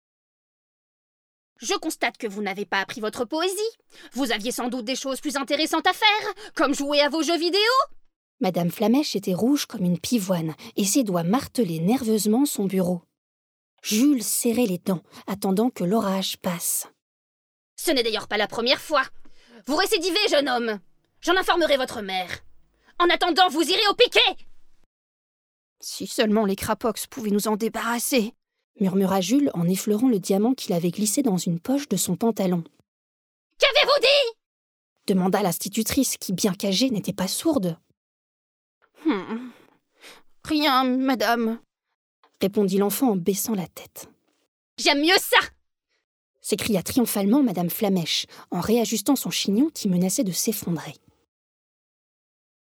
Narratrice
5 - 40 ans - Mezzo-soprano Soprano